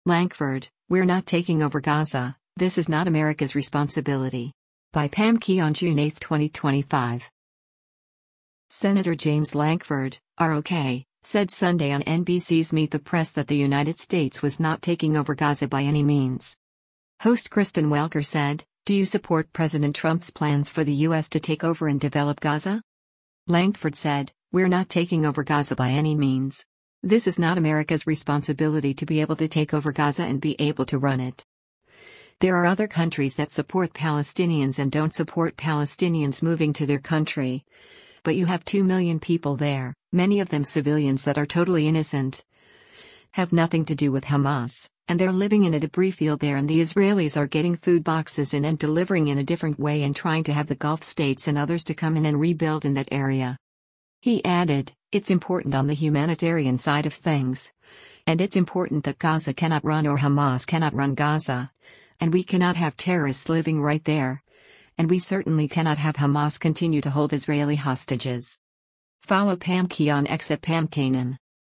Senator James Lankford (R-OK) said Sunday on NBC’s “Meet the Press” that the United States was “not taking over Gaza by any means.”
Host Kristen Welker said, “Do you support President Trump’s plans for the U.S. to take over and develop Gaza?”